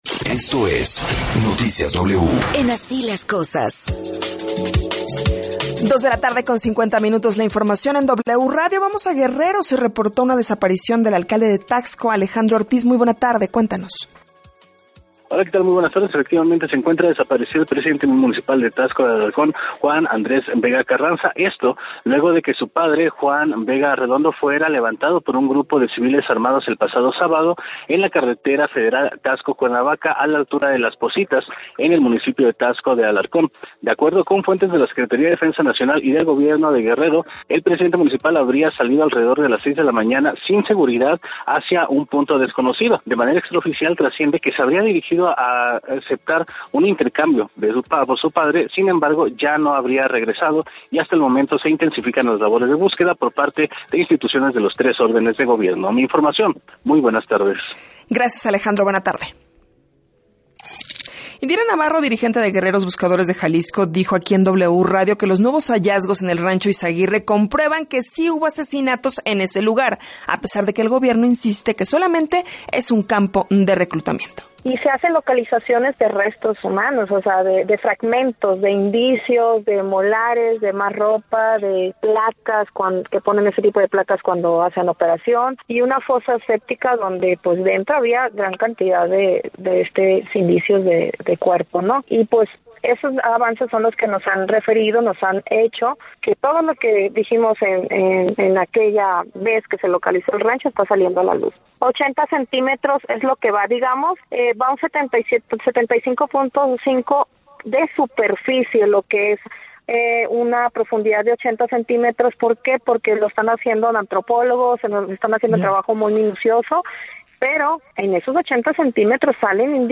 Resumen informativo